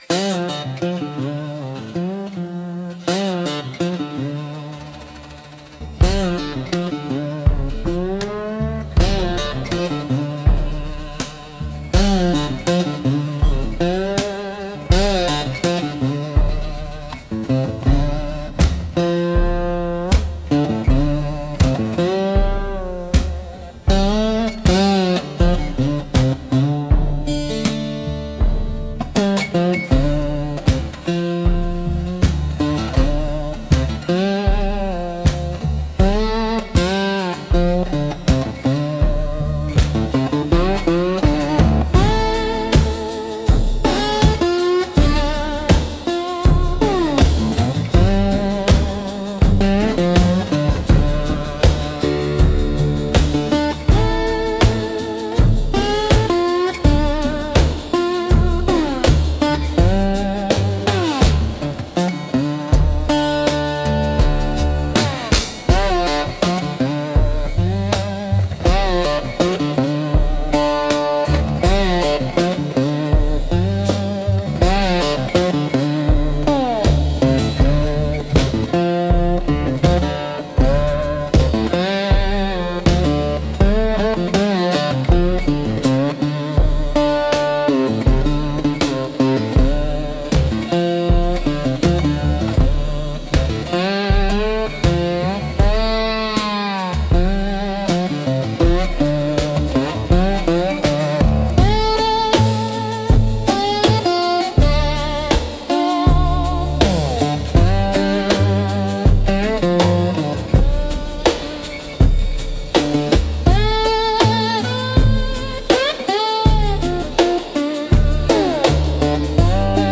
Instrumental - Outlaw’s Prayer